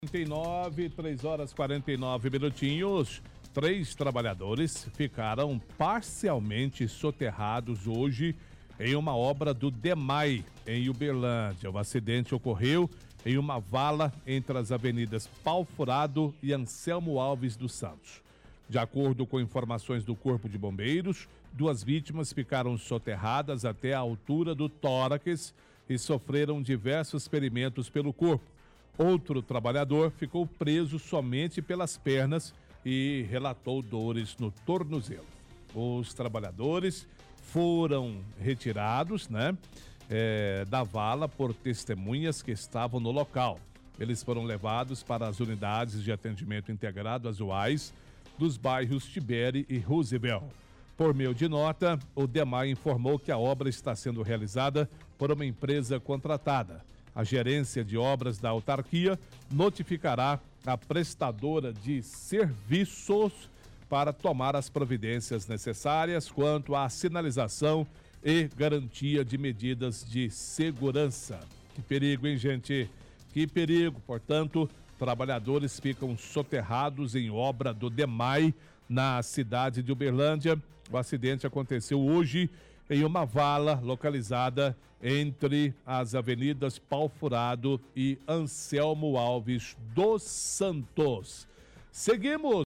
Apresentador lê notícia do jornal Diário de Uberlândia.